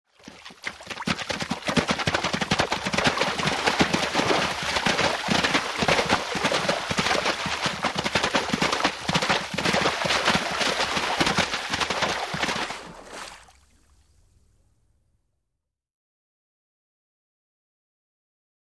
Footsteps, Horse In Water | Sneak On The Lot
Horse Running Steadily Through Shallow Water